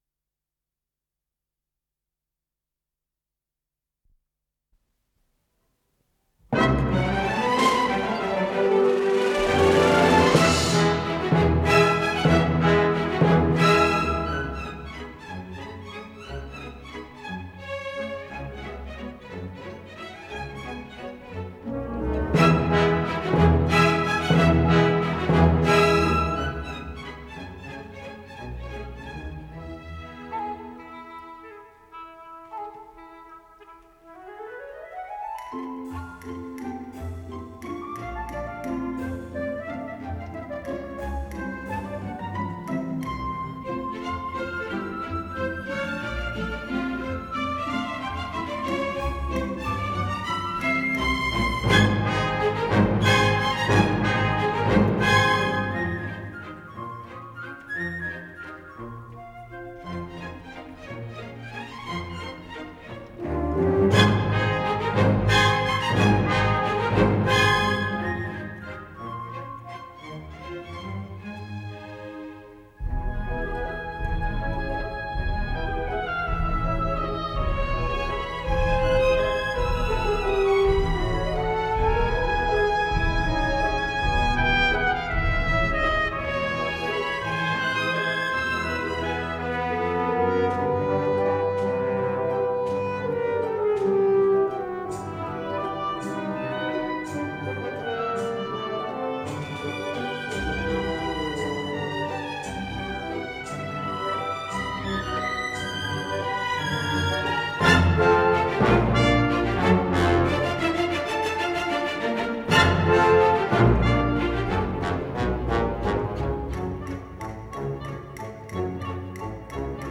с профессиональной магнитной ленты
ВариантДубль стерео